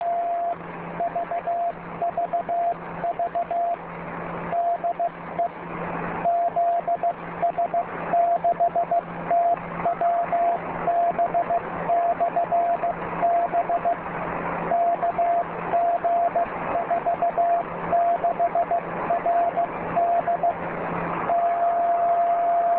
ZS6TWB / BCN realaudioat 1130z (Italy area I0) - 50044 Kc - Loc. KG46.